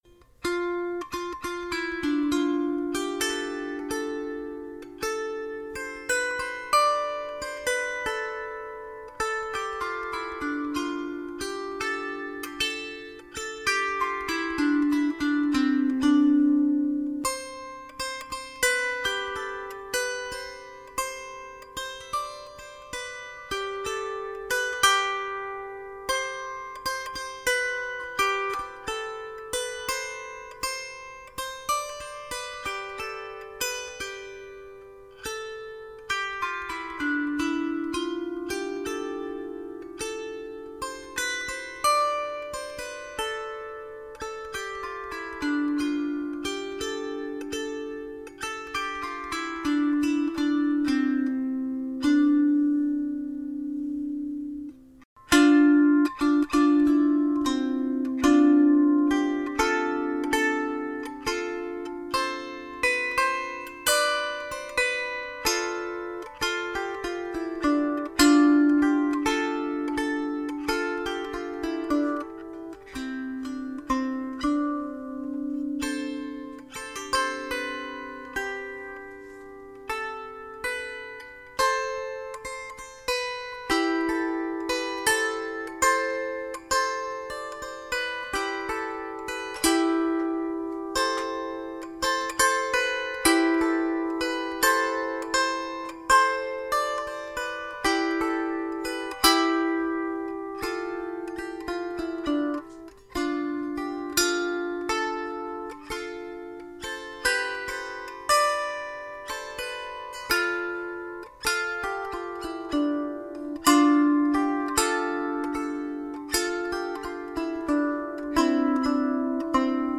Die klassische Lyra ist ein kleines Harfeninstrument.
Die 10 Saiten sind in der C-Dur Tonleiter gestimmt.
Klangbeispiel Lyra
lyra-melodie.mp3